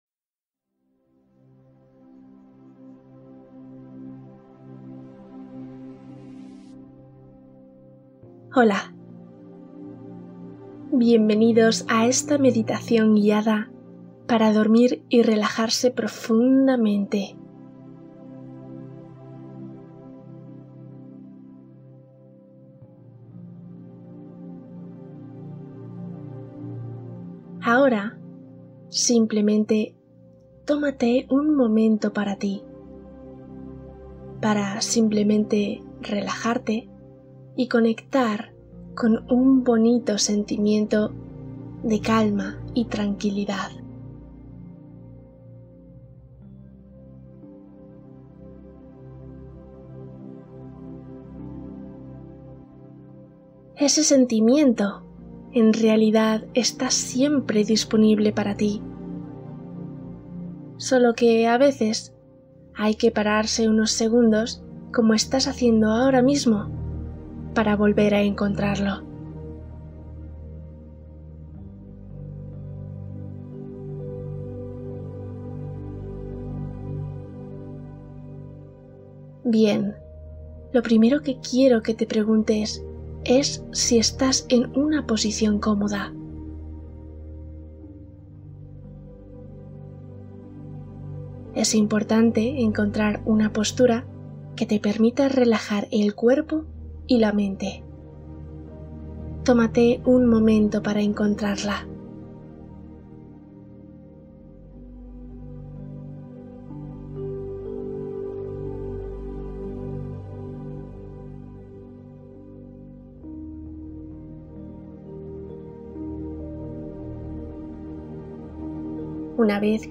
Cuento para dormir | Meditación con sonido del desierto